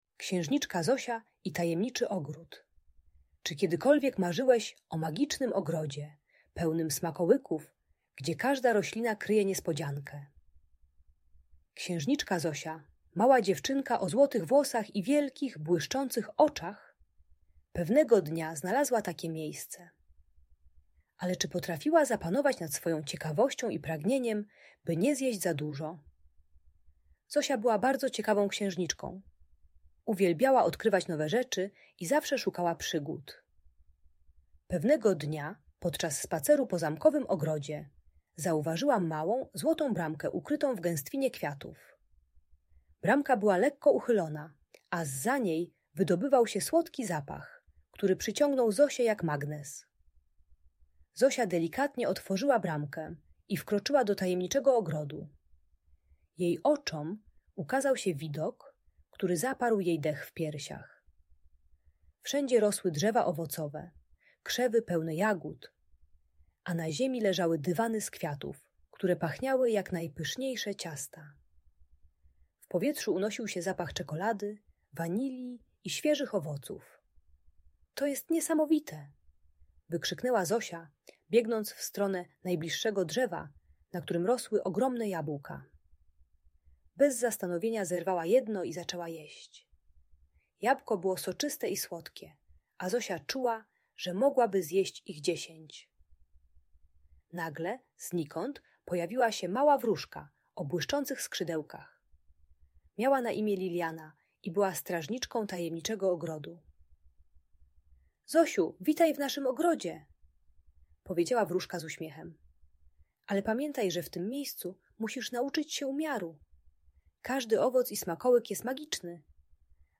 Księżniczka Zosia i Tajemniczy Ogród - Audiobajka